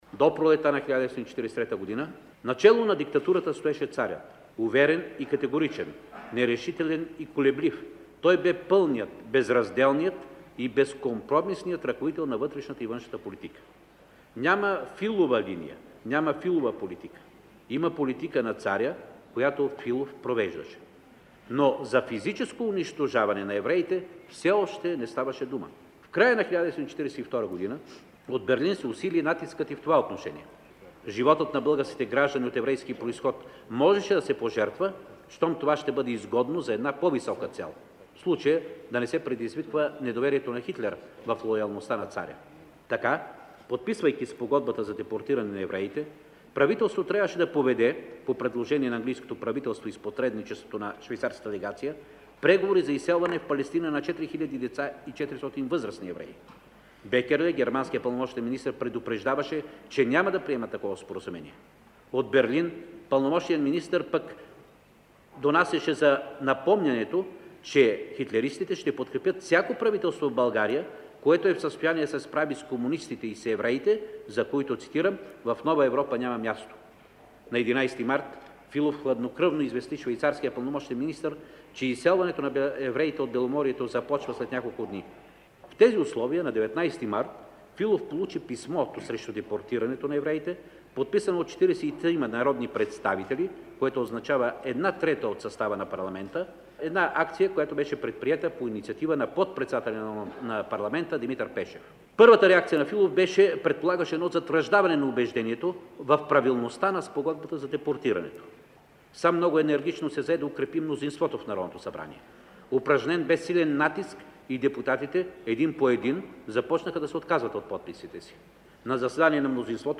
Професорът по история Илчо Димитров посочва, че спасяването на българските евреи става в исторически момент, когато Германия търпи вече загуби във войната, а акцията на Димитър Пешев „е постигнала целта си“. Документалният запис на изказването на проф. Илчо Димитров е от Международната кръгла маса на тема “45 години от спасяването на българските евреи от нацистката заплаха”, състояла се на 16 ноември 1988 година: